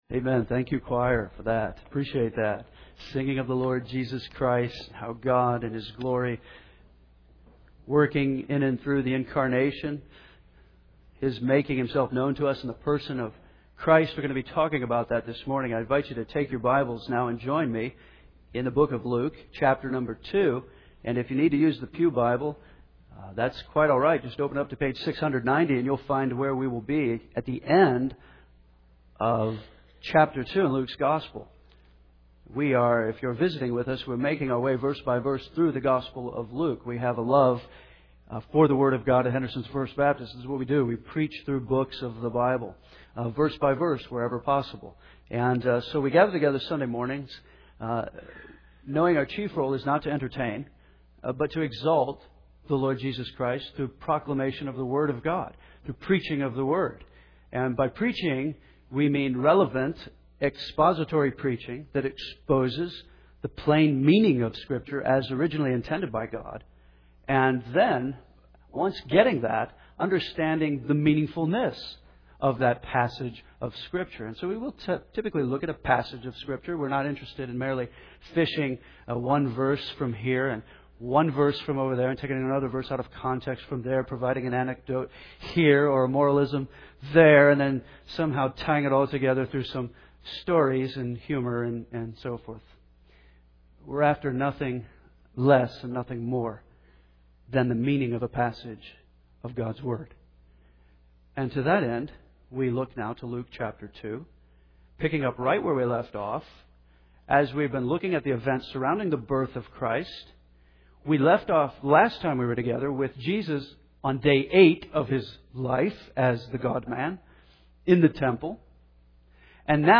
Henderson’s First Baptist Church, Henderson KY